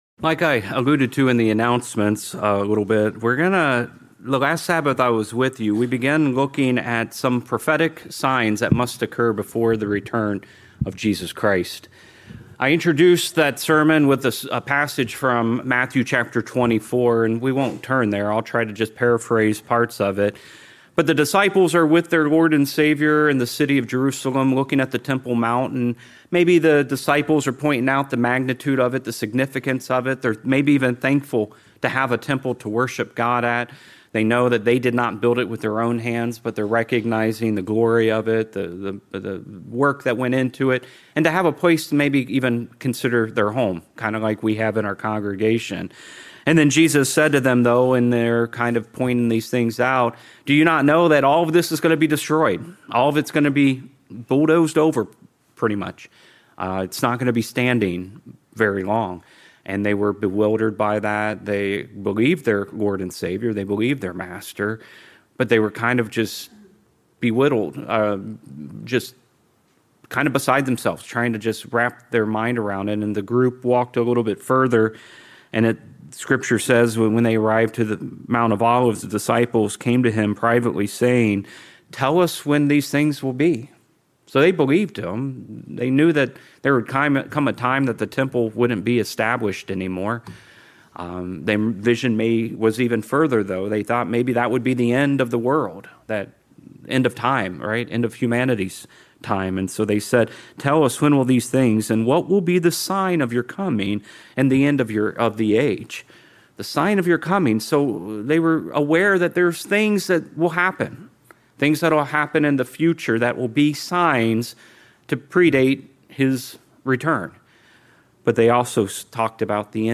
I introduced that sermon with a passage from Matthew chapter 24, and we won't turn there.